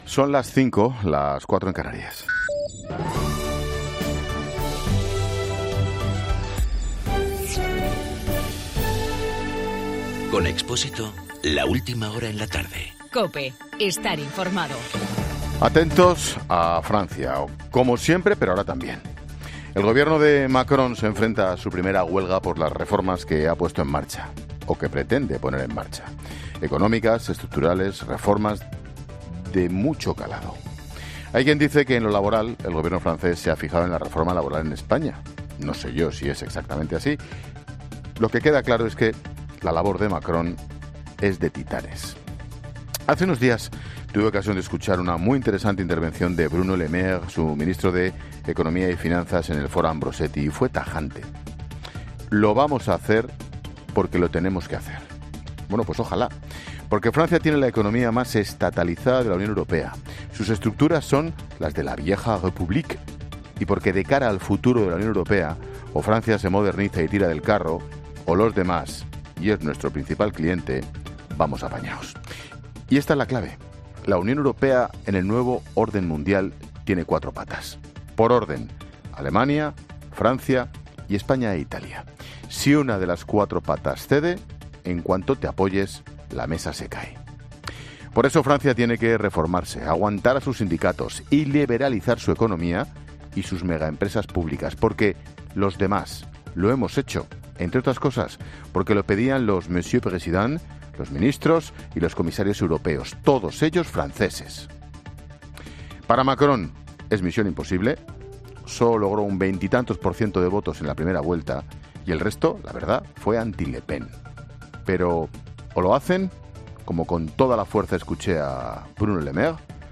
Monólogo de Expósito
Ángel Expósito analiza en su monologo de las 17 horas la situación económica de Francia. Macron lleva poco más de medio año en el poder y ya va a tener que enfrentarse a su primera huelga por las reformas que ha puesto en marcha.